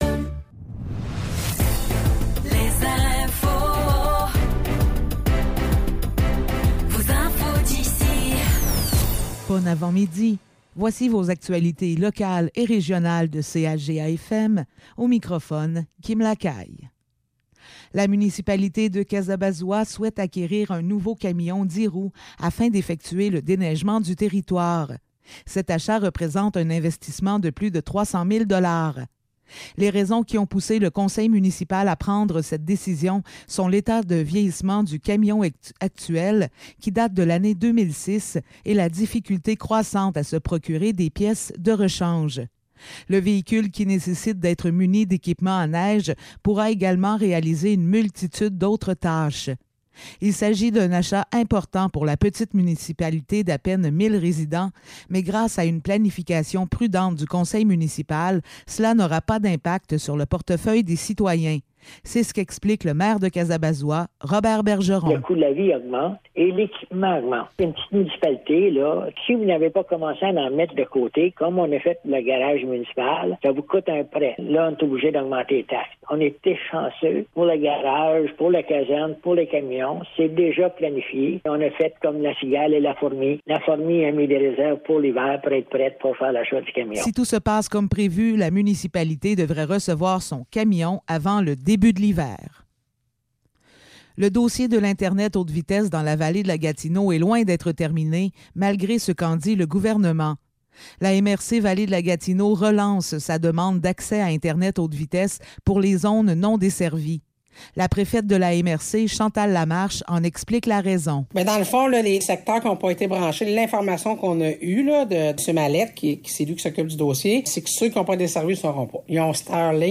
Nouvelles locales - 11 juillet 2024 - 10 h